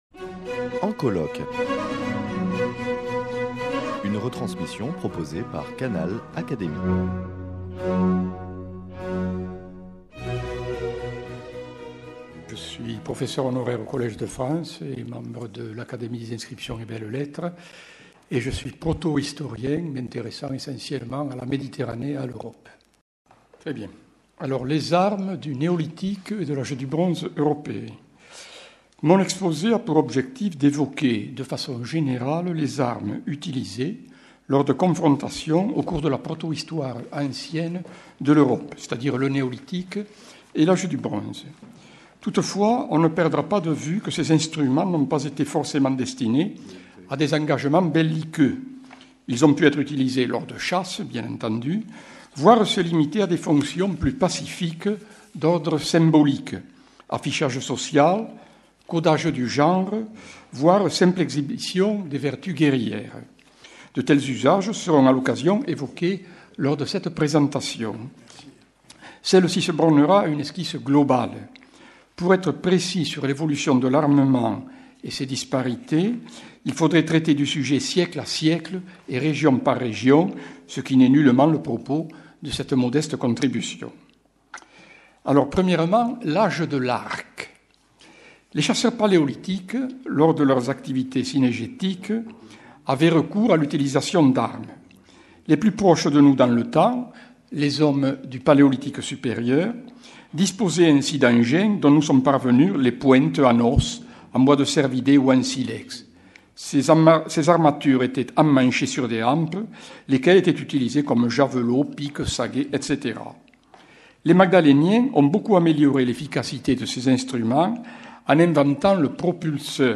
Communication de Jean Guilaine, professeur au Collège de France et membre l’Académie des inscriptions et belles-lettres, prononcée le 14 octobre 2016 lors des journées d’étude « Guerre et technique » organisées dans le cadre du programme de recherche « Guerre et société » soutenu par la Fondation Simone et Cino del Duca et l’Académie des sciences morales et politiques.